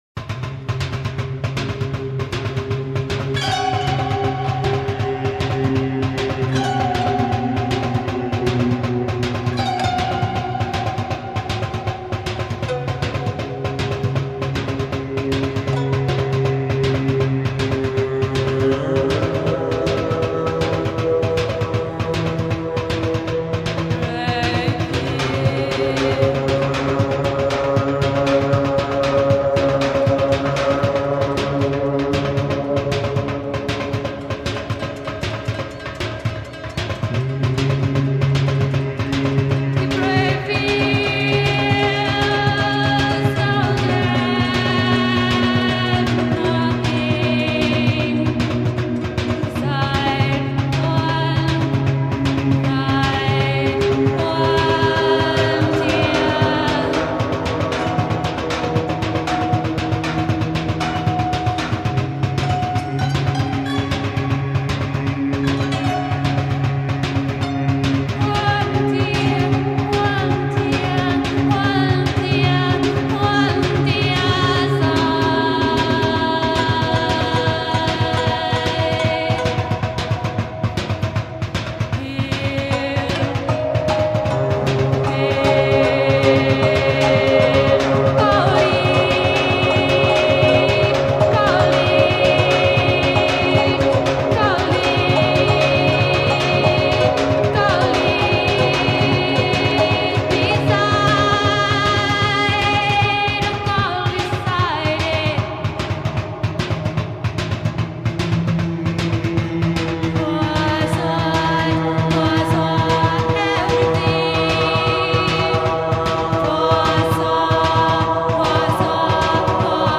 This song is a demo from their first self-titled release.